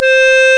1 channel
clarint2.mp3